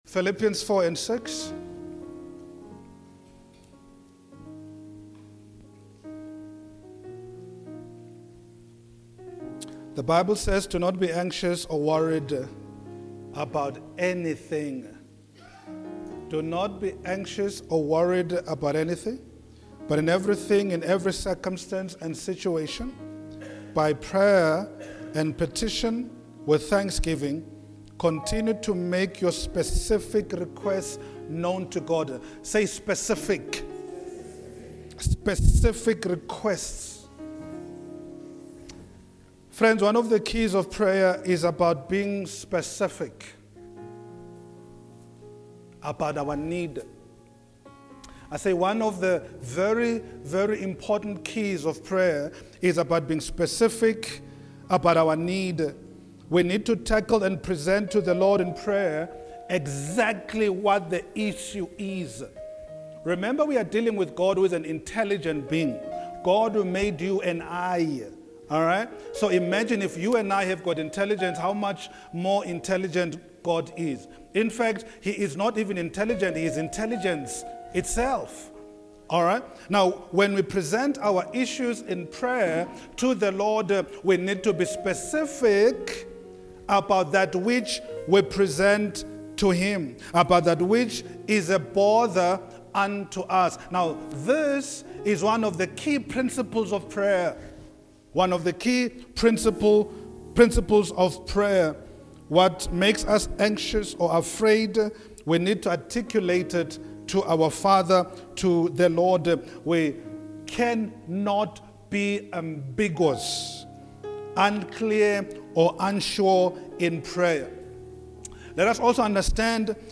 28-July-2024-Sunday-Service-Teaching-The-wisdom-of-prayer.mp3